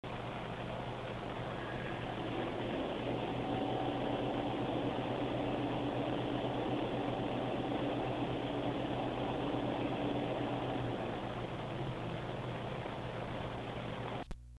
FrostyTech Acoustic Sampling Chamber